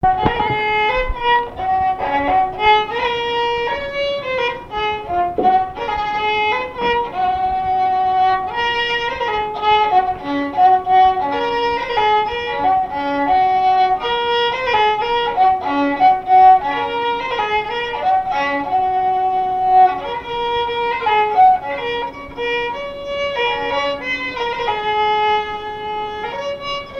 Genre laisse
Répertoire de marches de noce et de danse
Pièce musicale inédite